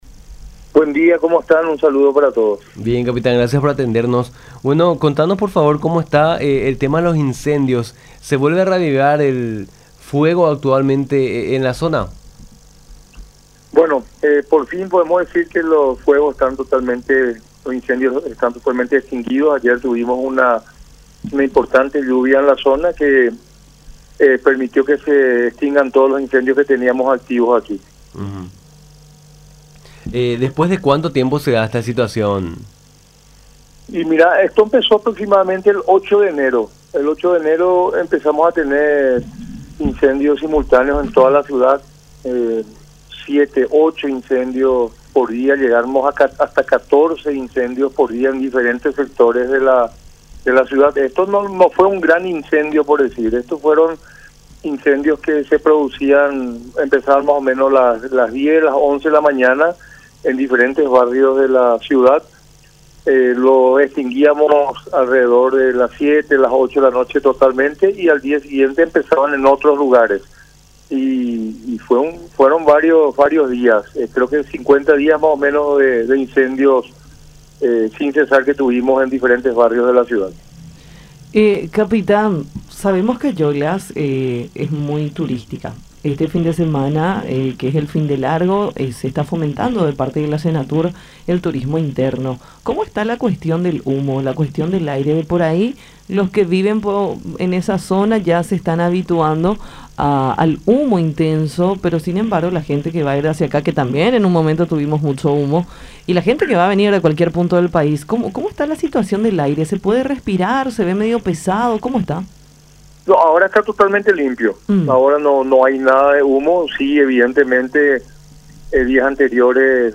en charla con Nuestra Mañana por La Unión